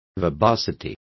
Also find out how verbosidad is pronounced correctly.